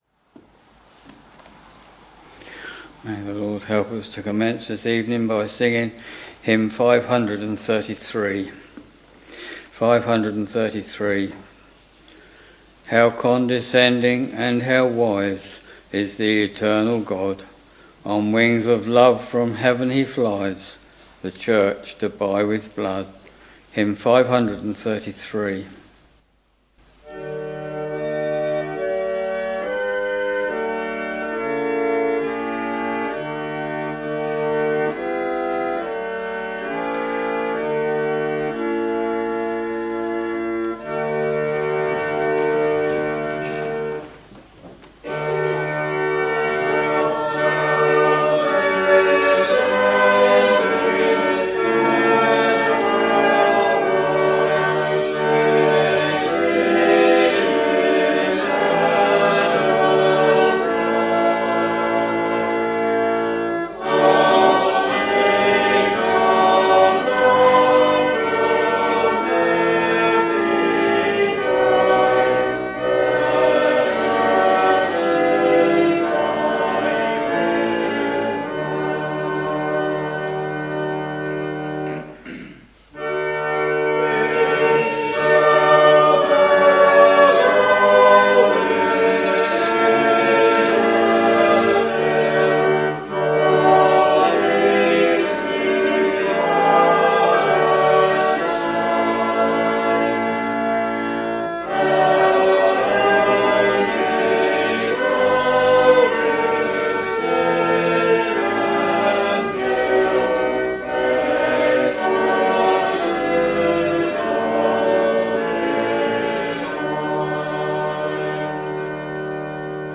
Week Evening Service Preacher
Hymns: 533, 854, 949 Reading: John 16 Bible and hymn book details Listen Download File